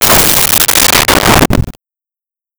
Door Church Close
Door Church Close.wav